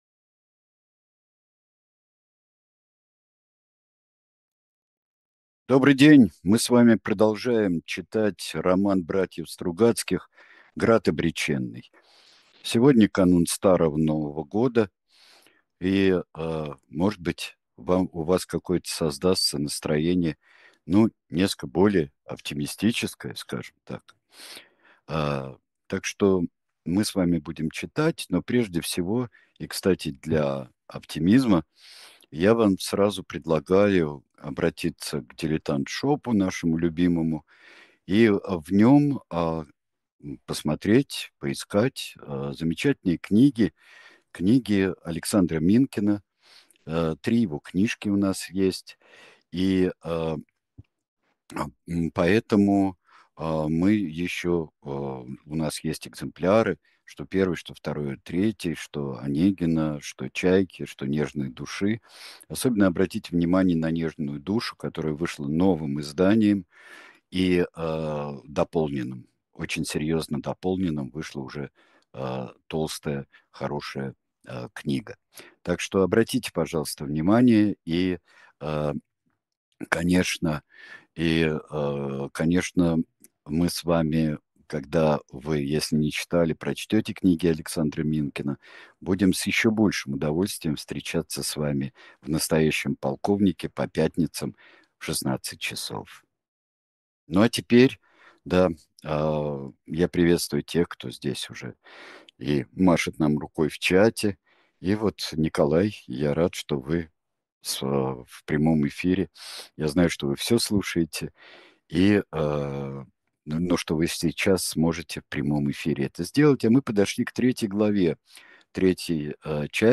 Произведение читает Сергей Бунтман
grad-obrechennyj-bratev-strugaczkih-—-chast-12.-chitaet-sergej-buntman.mp3